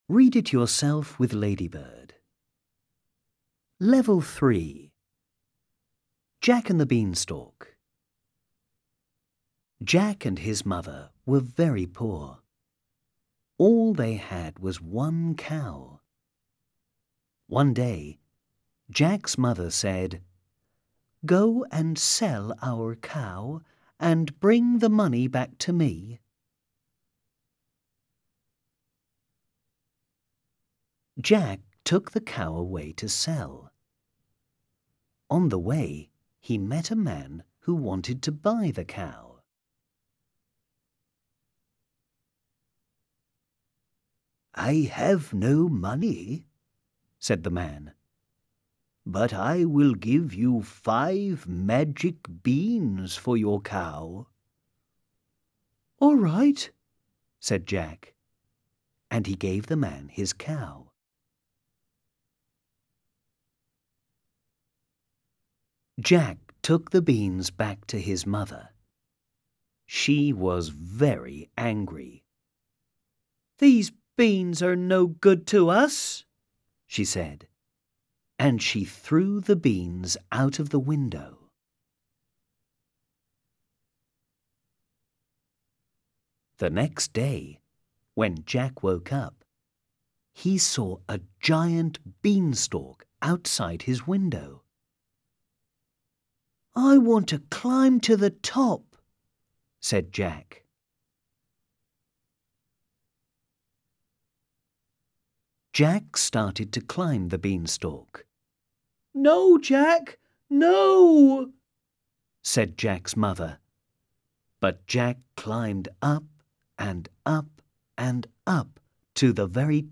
Audio UK